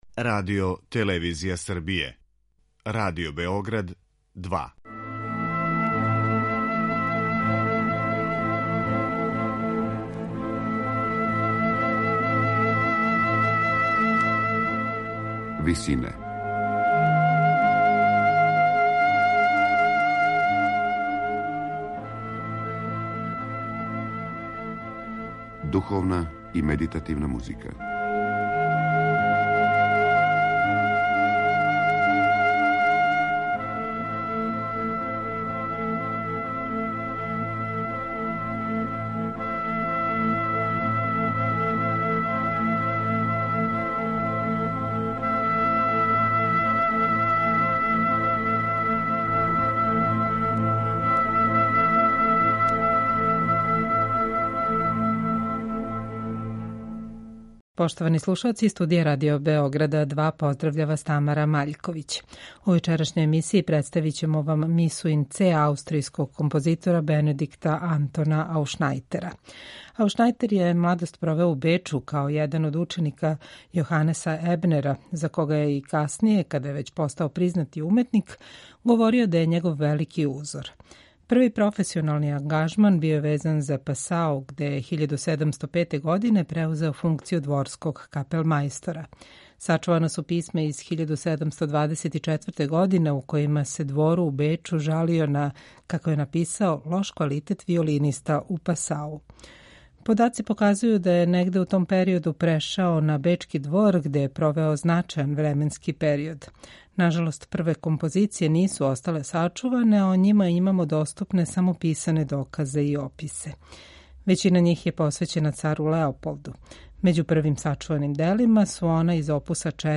У вечерашњој емисији емитујемо Мису ин Це аустријског барокног композитора Бенедикра Антона Ауфшнајтера.
Међу најранијим сачуваним композицијама овог аутора, налази се низ од шест миса из опуса 6, насталих током Ауфшнајтеровог ангажмана на двору у Пасауу. Мису ин Це изводи ансамбл Нове дворске капеле у Инсбруку.